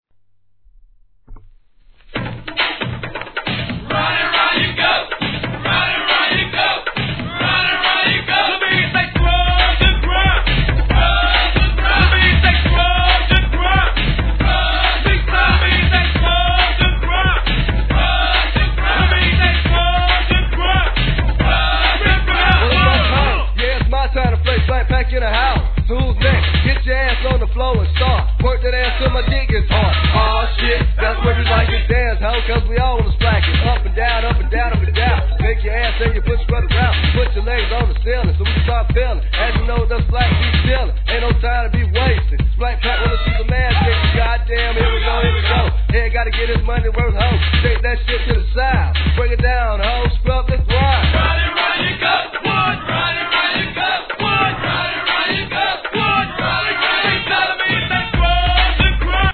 1. G-RAP/WEST COAST/SOUTH